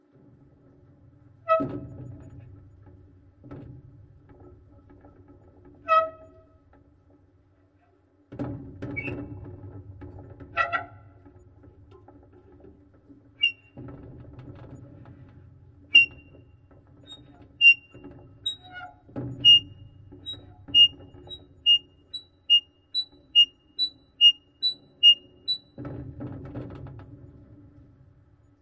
痛苦的吱吱声锈
描述：这个非常痛苦。一个旧的金属铰链。
标签： 吱吱 痛苦 生锈 铰链 双耳 金属 吱吱
声道立体声